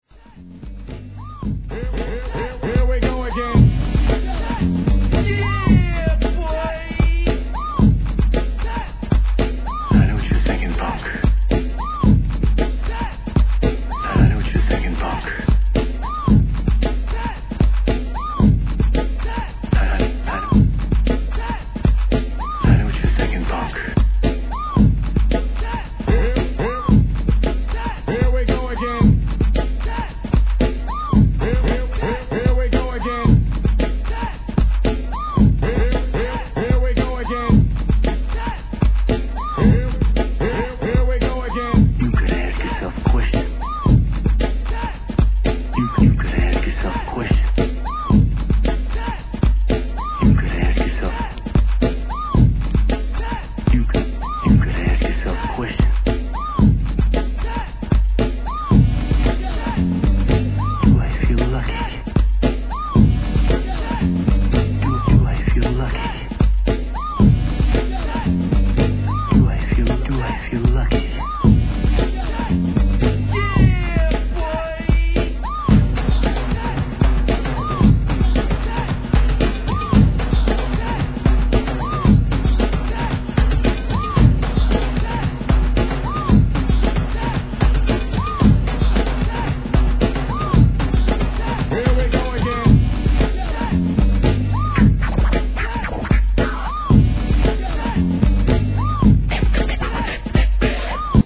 HIP HOP/R&B
1988年のOLD SCHOOLな定番ブレイク・ビーツ物!!
古き良きスクラッチ、サンプラーに温故知新を思います。